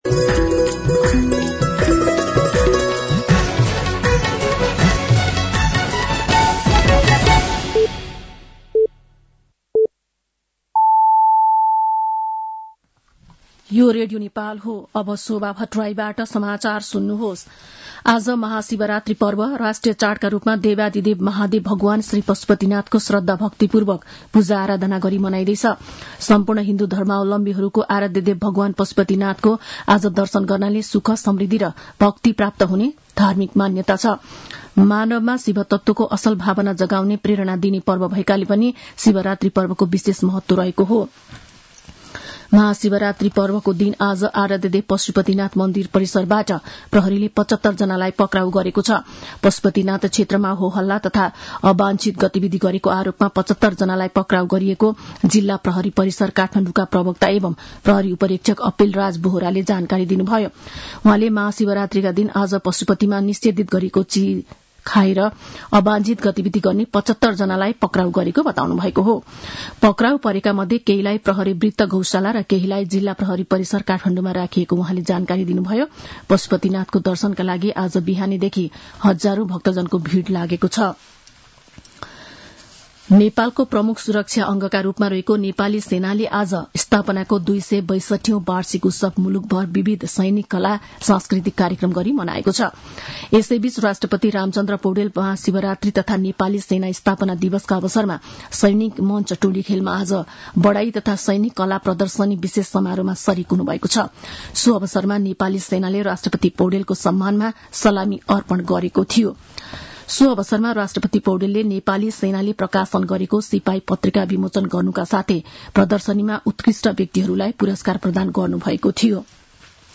साँझ ५ बजेको नेपाली समाचार : १५ फागुन , २०८१